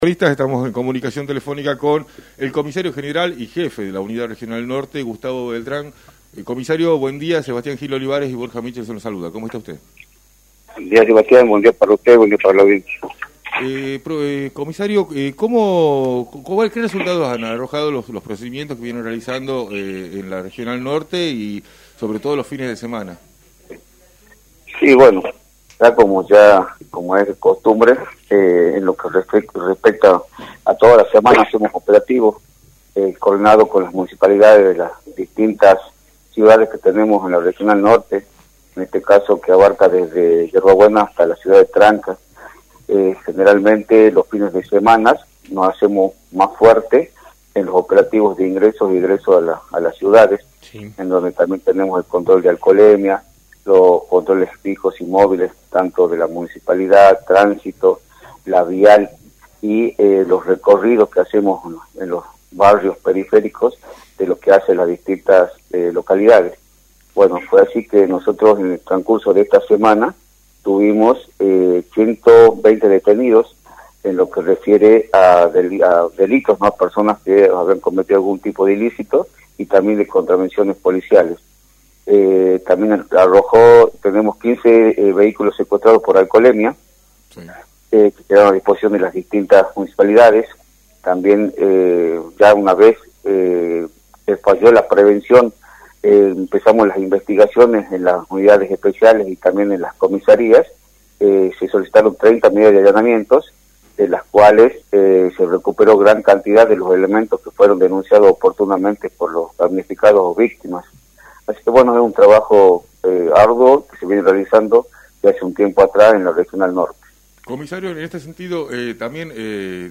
En diálogo con Libertad de Expresión, el Comisario General Gustavo Beltrán, jefe de la Unidad Regional Norte, brindó detalles sobre los operativos policiales llevados a cabo en la región durante la última semana.